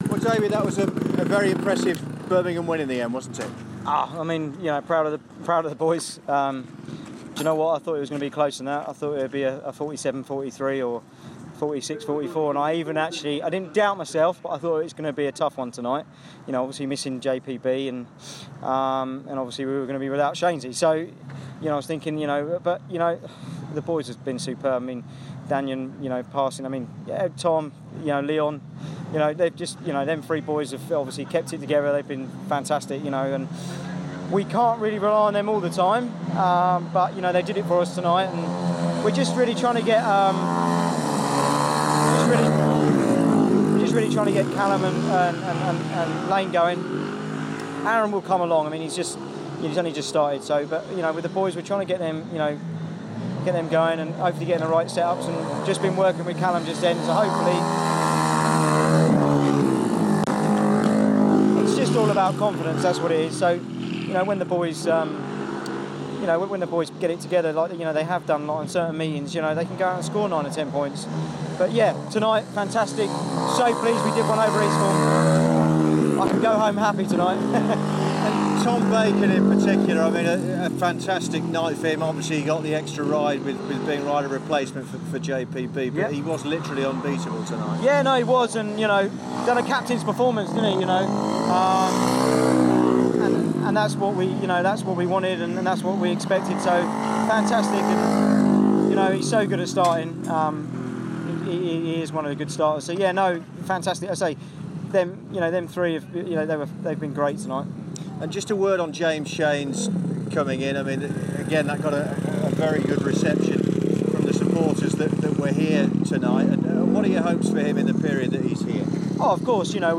He was speaking to BBC WM in a noisy pit area after their 54-36 win over Eastbourne on Wednesday...